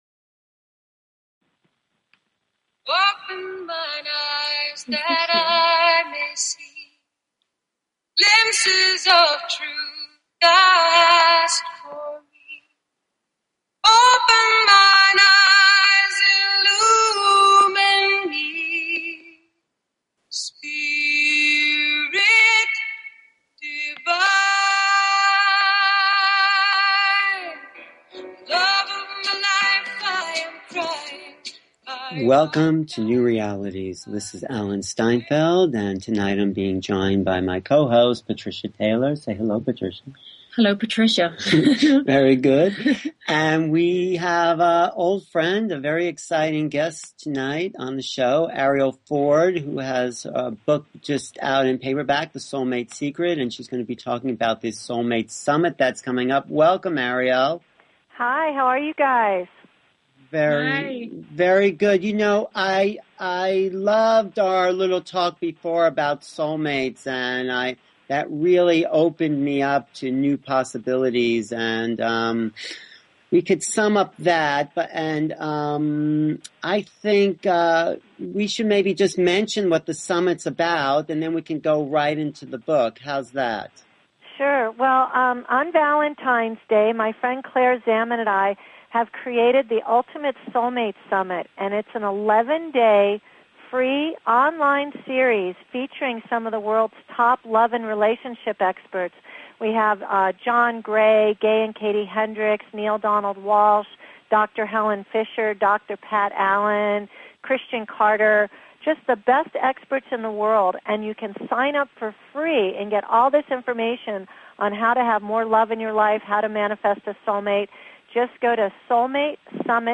Talk Show Episode, Audio Podcast, New_Realities and Courtesy of BBS Radio on , show guests , about , categorized as
Courtesy of BBS Radio